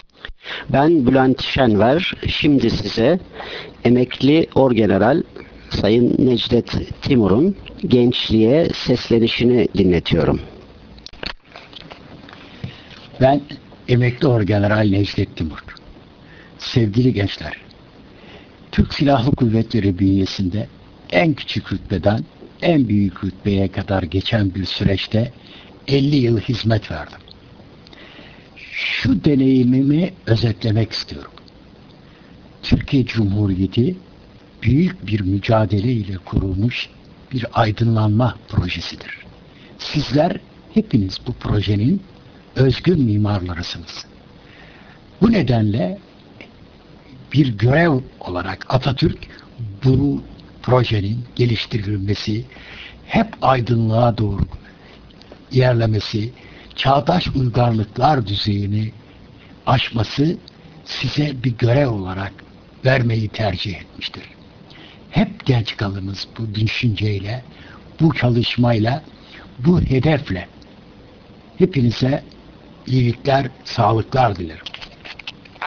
Kendi Sesiyle